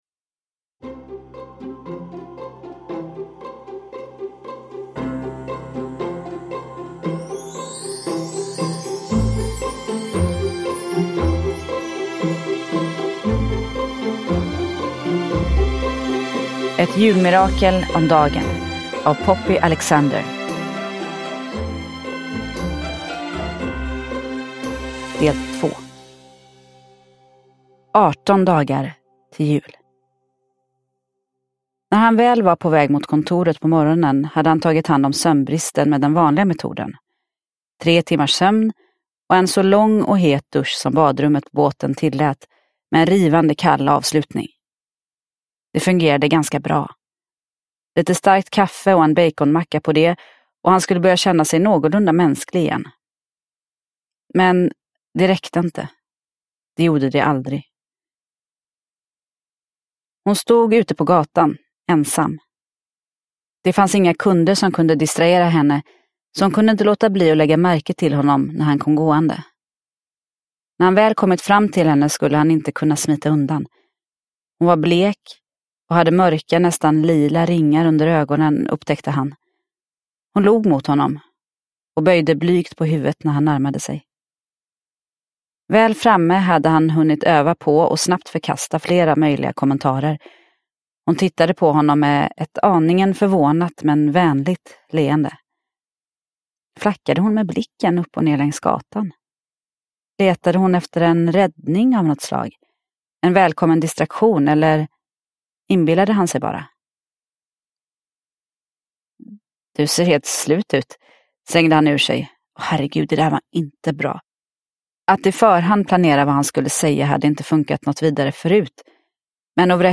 Ett julmirakel om dagen. Del 2 – Ljudbok – Laddas ner